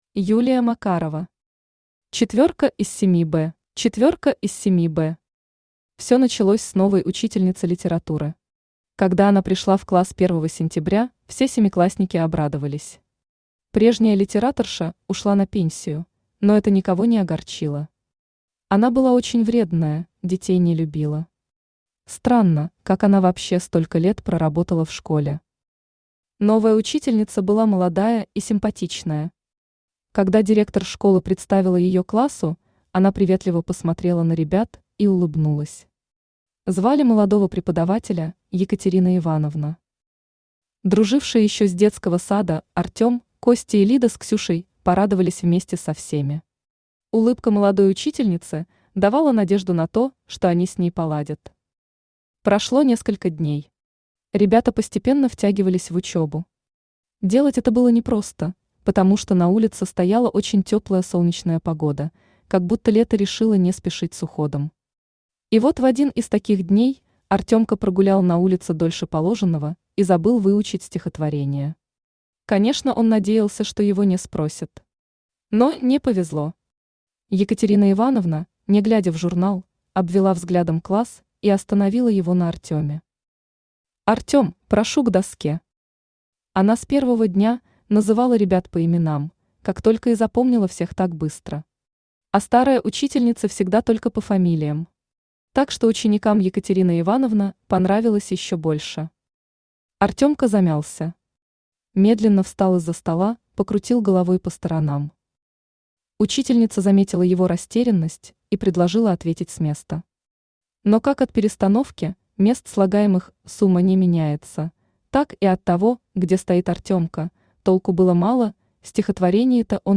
Аудиокнига Четвёрка из 7 «Б» | Библиотека аудиокниг
Aудиокнига Четвёрка из 7 «Б» Автор Юлия Макарова Читает аудиокнигу Авточтец ЛитРес.